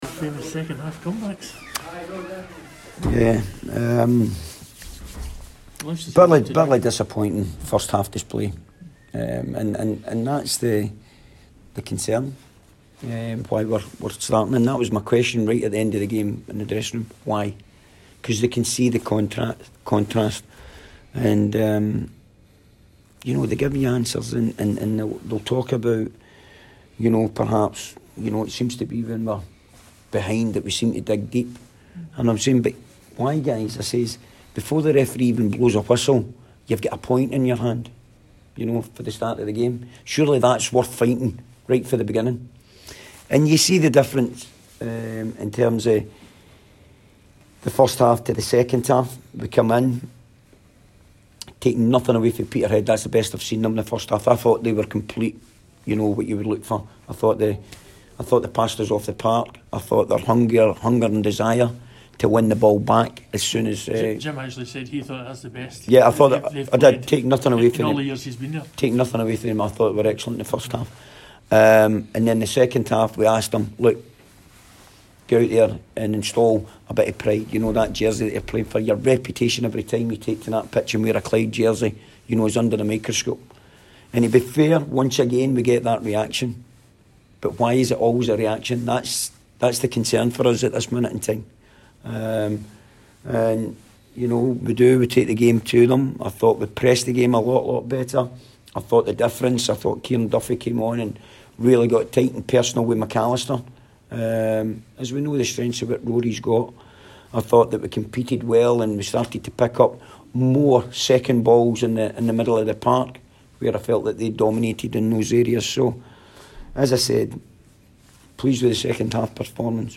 press conference after the Ladbrokes League 1 match.